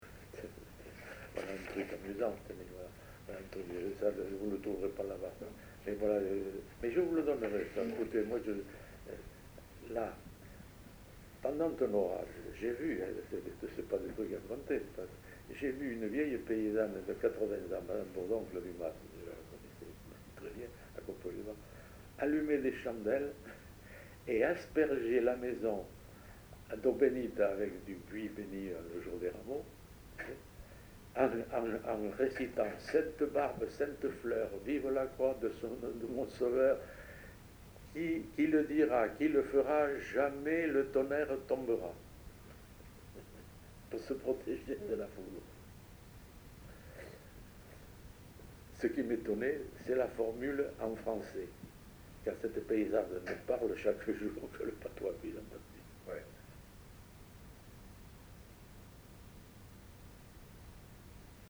Genre : conte-légende-récit
Effectif : 1
Type de voix : voix d'homme
Production du son : récité
Classification : prière